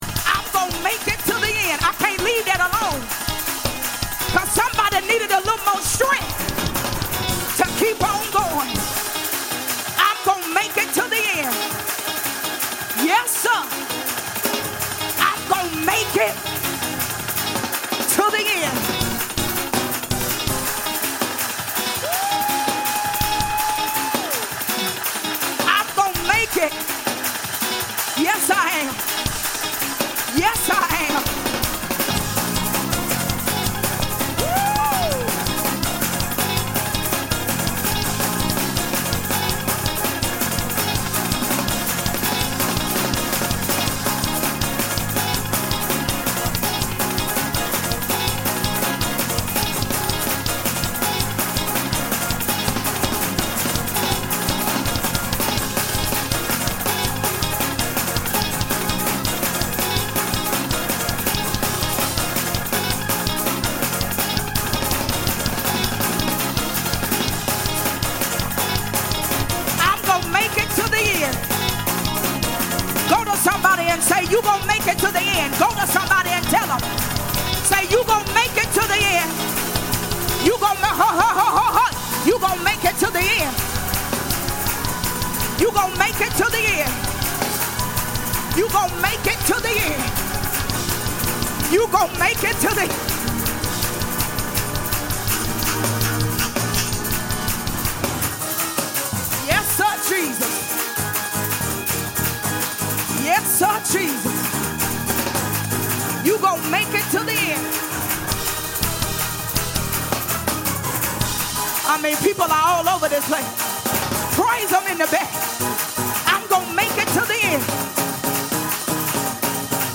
Stand On Your Word Praise Break